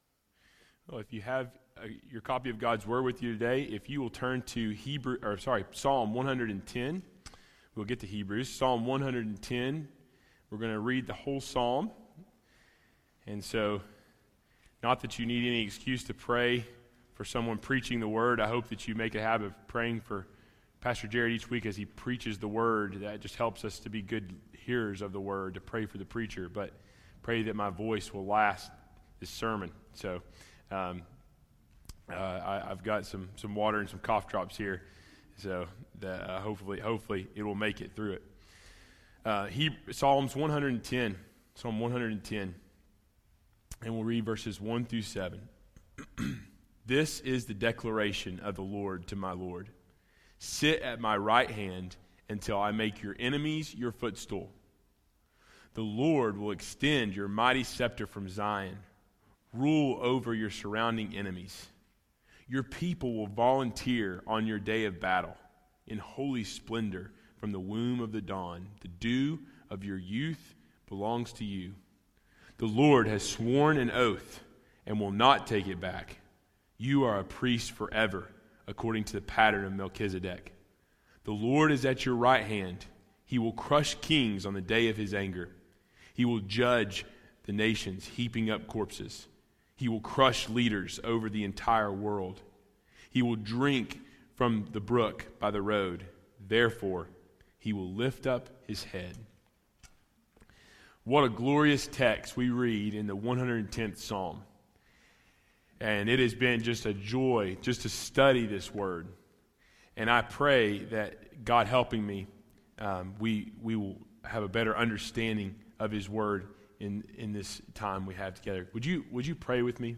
Sermon Audio 2018 April 15